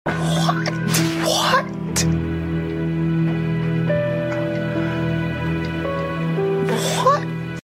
Backyard Baseball butterfingers power up sound effects free download